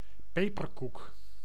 Summary Description Nl-peperkoek.ogg male voice pronunciation for " peperkoek " .
Nl-peperkoek.ogg